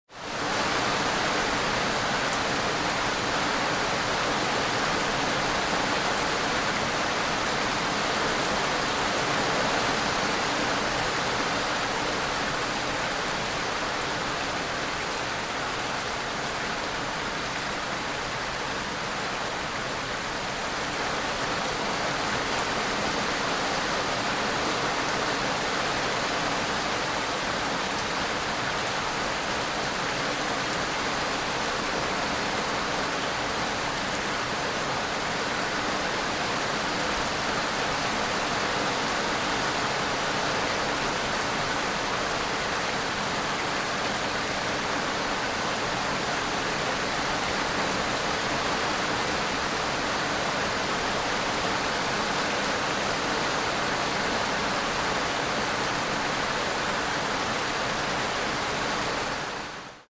دانلود آهنگ باران 1 از افکت صوتی طبیعت و محیط
دانلود صدای باران 1 از ساعد نیوز با لینک مستقیم و کیفیت بالا
جلوه های صوتی